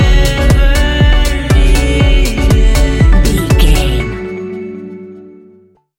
Ionian/Major
F♯
house
electro dance
synths
techno
trance